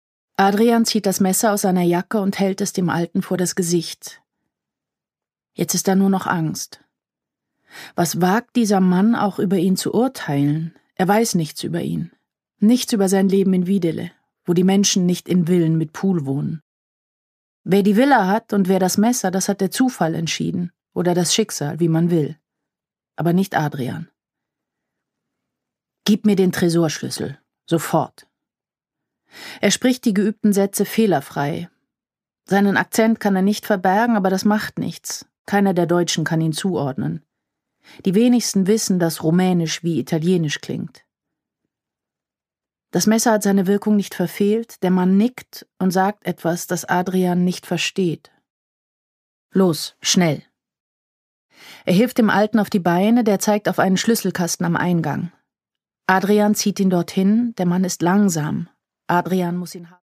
Produkttyp: Hörbuch-Download
Gelesen von: Nina Kunzendorf